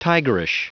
Prononciation du mot tigerish en anglais (fichier audio)
Prononciation du mot : tigerish